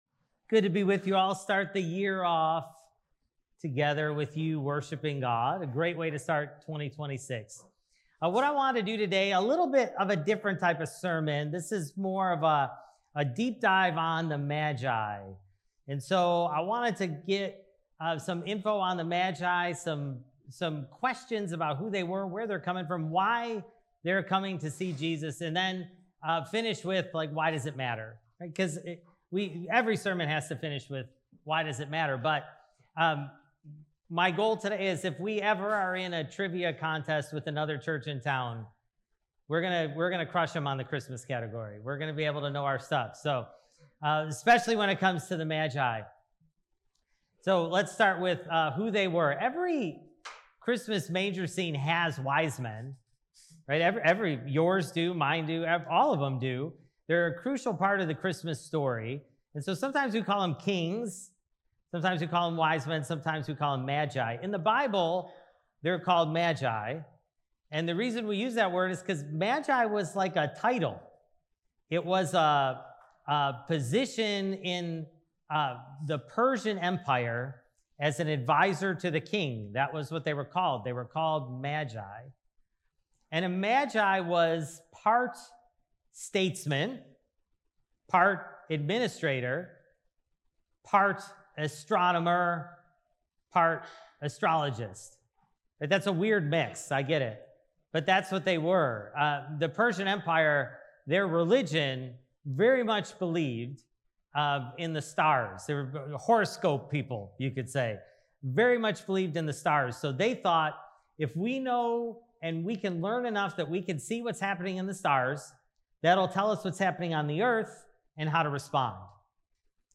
Sermon: Why the Magi? (Matthew 2:1-12)